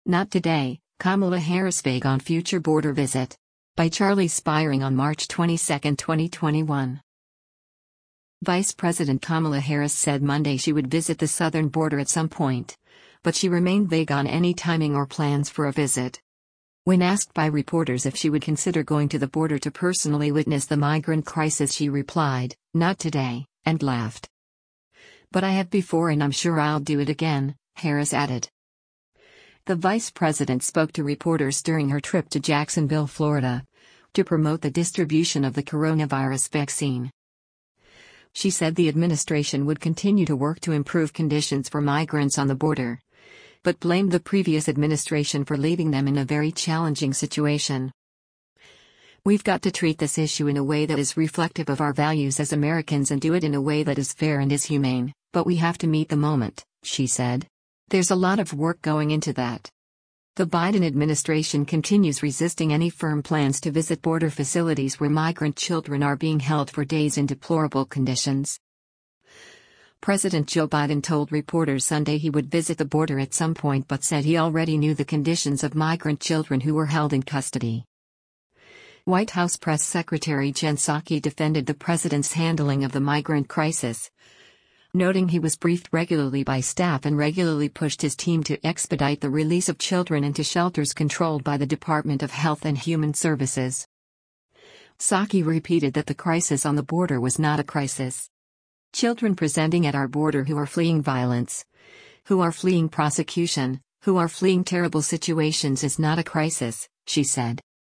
When asked by reporters if she would consider going to the border to personally witness the migrant crisis she replied, “Not today,” and laughed.
The vice president spoke to reporters during her trip to Jacksonville, Florida, to promote the distribution of the coronavirus vaccine.